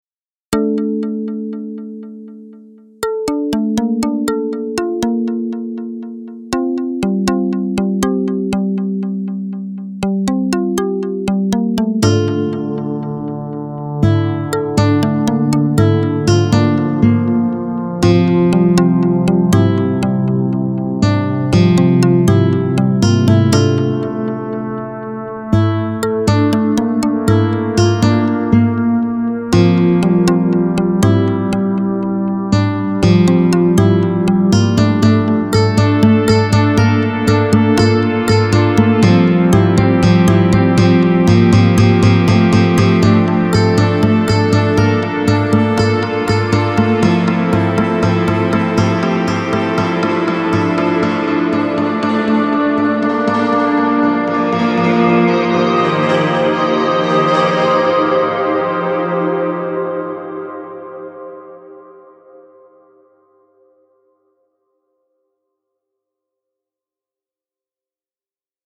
Das zweite Schnipsel hat in seiner Grundidee schon 8 Jahre auf dem Buckel und war ursprünglich für unsere Metall-Band geschmiedet, aber nie zum Einsatz gekommen...